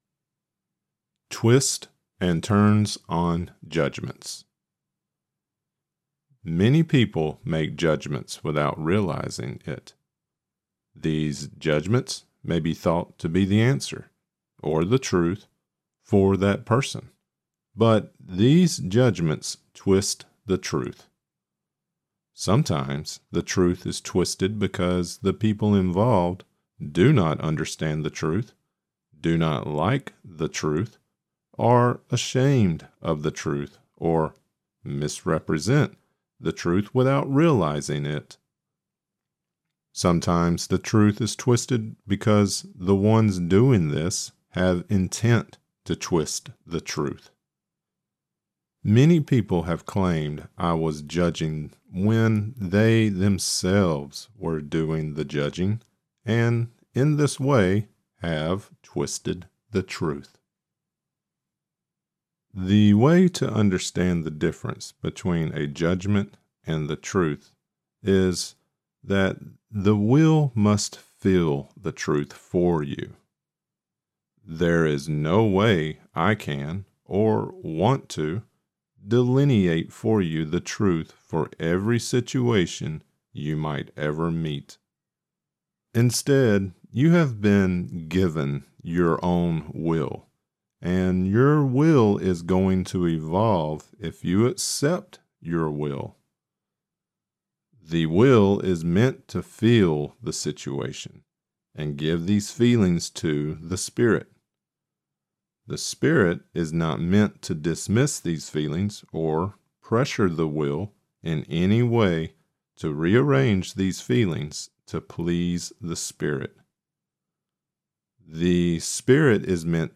This is part 17 of the Right Use of Will and this lecture includes: Twists and Turns on Judgments There are nine sections in part 17: * Feel for Truth * Death Seeking Denial * Forms of Denial * The Media and Advertisements * Influence over Children * Recovery of Lost Will * Reality on Earth * Increase Openness * Belief Systems Lecture Created Transcript Blockchain Twists and Turns on Judgments 01/08/2026 Twists and Turns on Judgments (audio only) 01/08/2026 Watch Right Use of Will part 17 lecture: Right Use of Will lectures are also located on the Cosmic Repository video site .